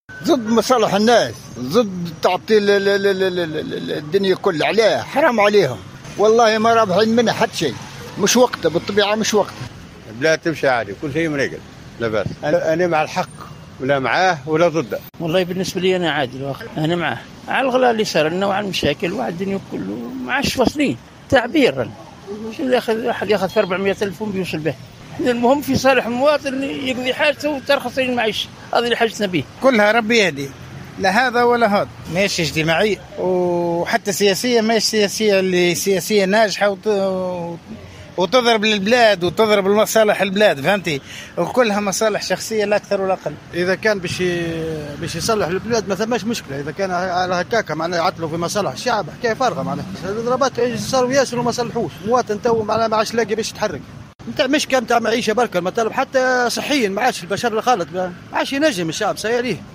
مدنين : رصد ل اراء المواطنين للاضراب اليوم بين رافض و مؤيد / ميكرو ترتوار *تسجيل - راديو أوليس أف أم